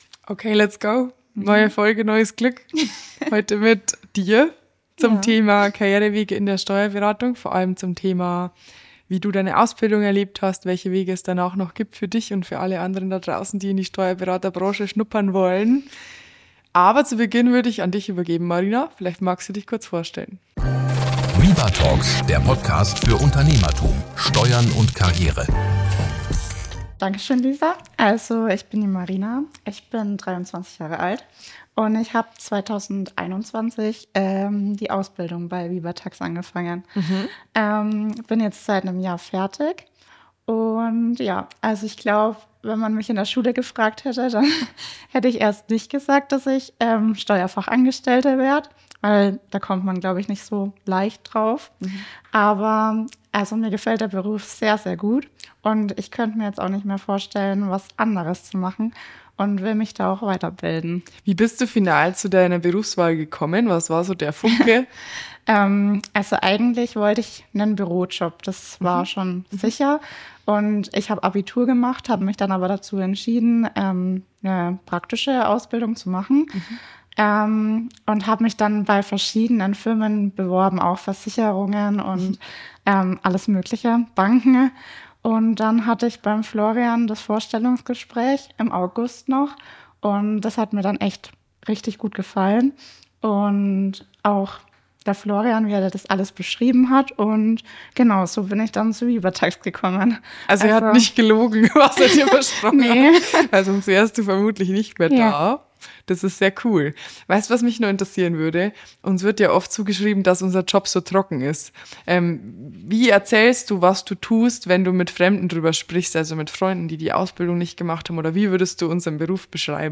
Ein ehrliches Gespräch über Zufälle, Leidenschaft und die kleinen Klick-Momente, die im Berufsleben alles ins Rollen bringen können.